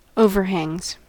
Ääntäminen
Ääntäminen US Haettu sana löytyi näillä lähdekielillä: englanti Käännöksiä ei löytynyt valitulle kohdekielelle. Overhangs on sanan overhang monikko.